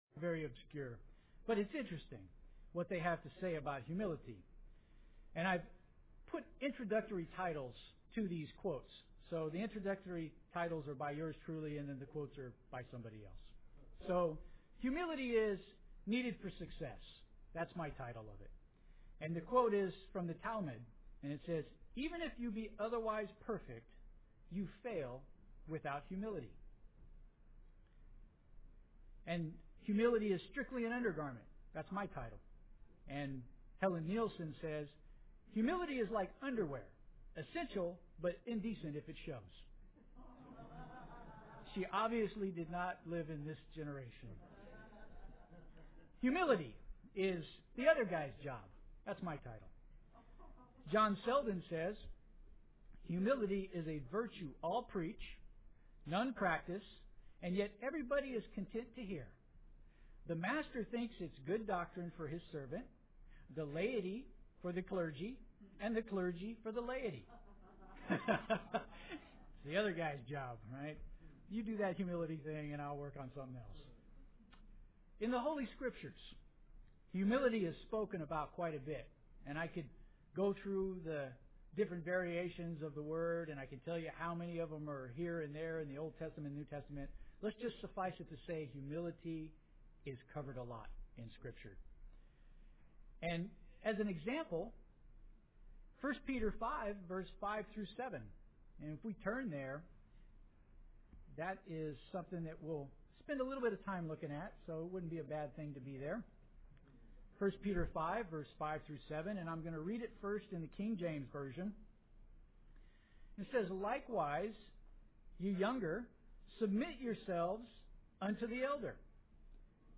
Given in San Francisco Bay Area, CA
UCG Sermon Studying the bible?